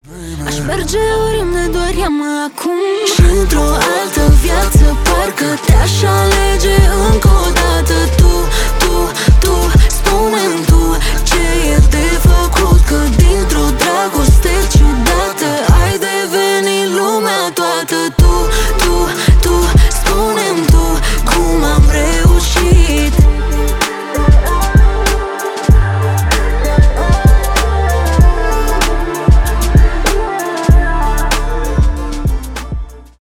поп , дуэт